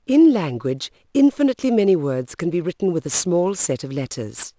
spraak in een vrij veld.
Het originele geluidfragment werd opgenomen in een dode kamer.